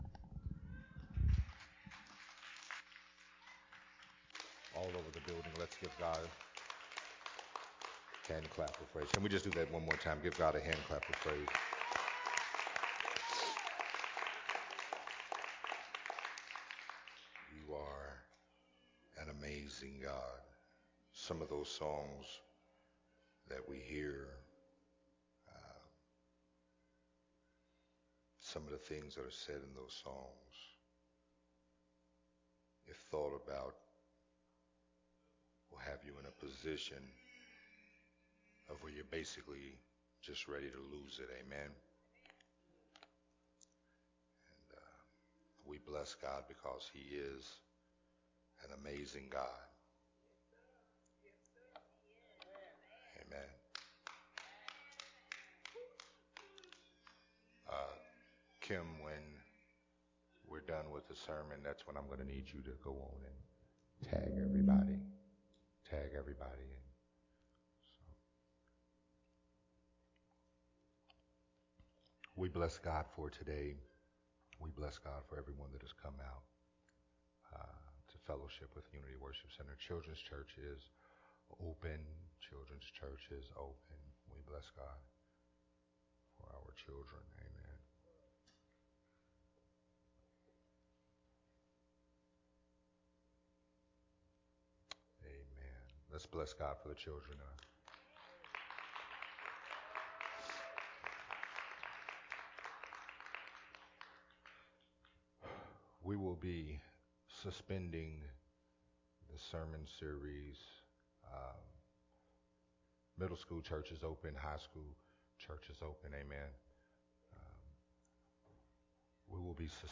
recorded at Unity Worship Center